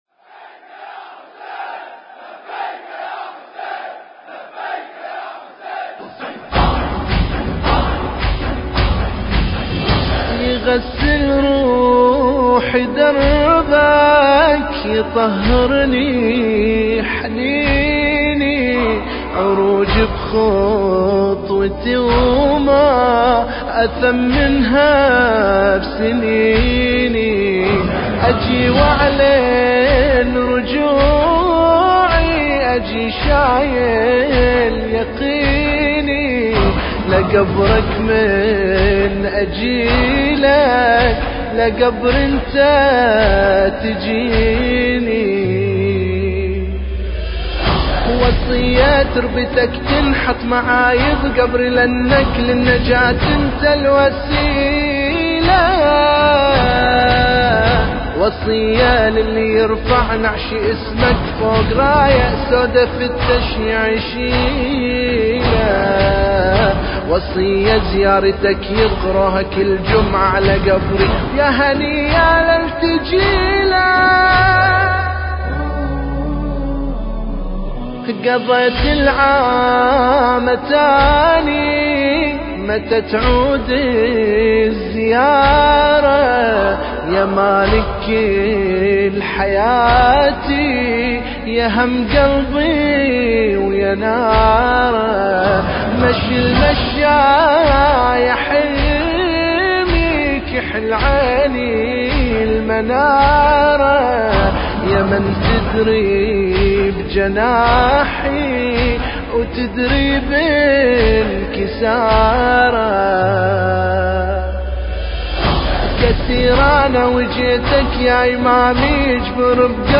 المراثي